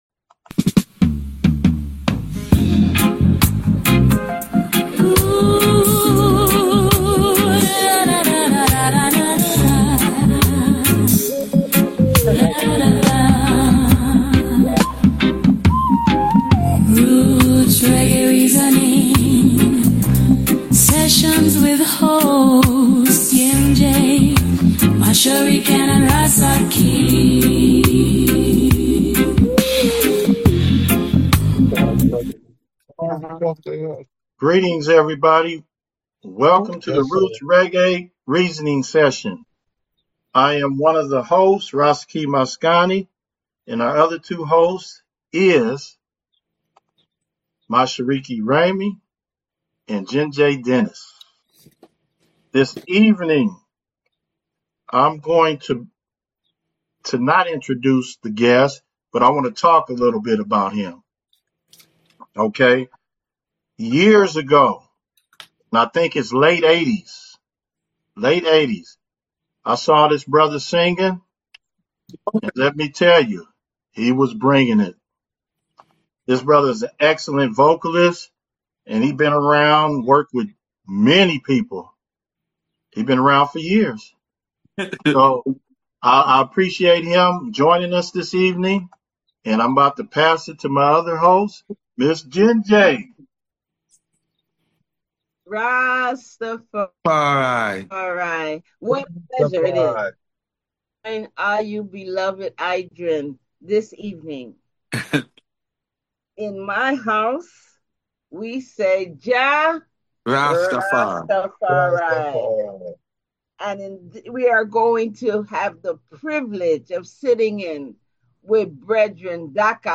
The CEN Show Roots Reggae Reasoning Session